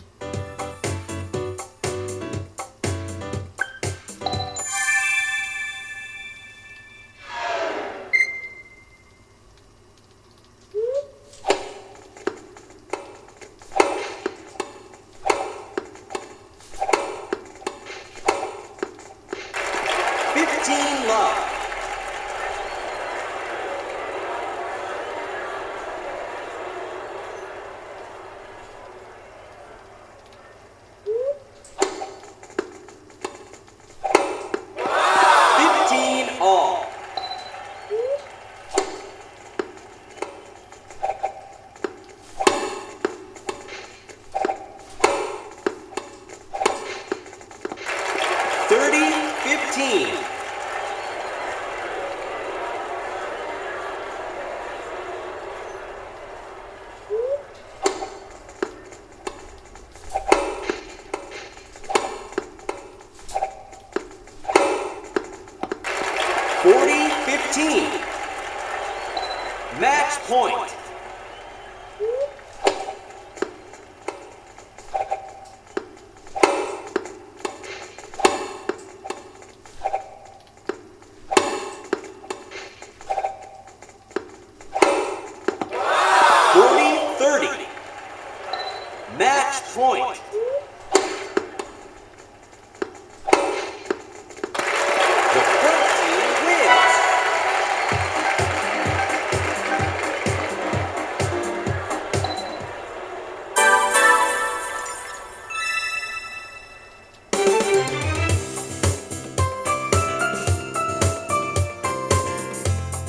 Ascoltate il ritmo della pallina e cercate di capire l’andamento del gioco.